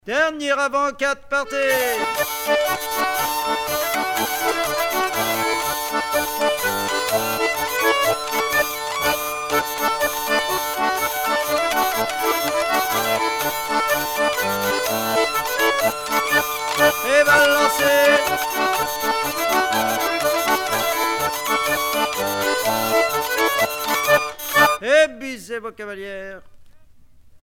danse : quadrille : avant-quatre
Pièce musicale éditée